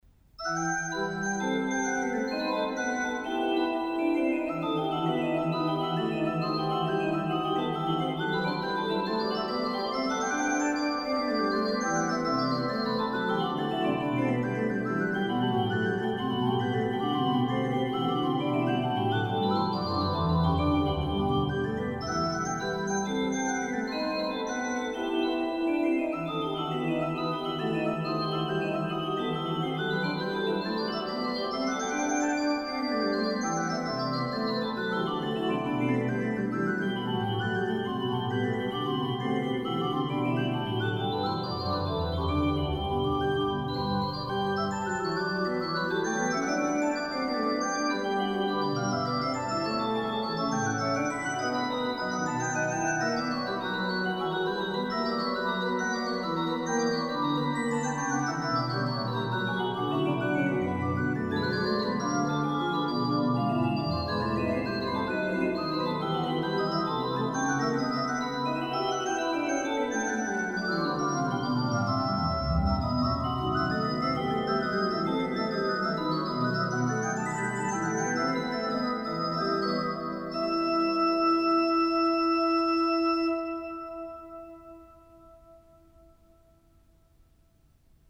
Transcription pour orgue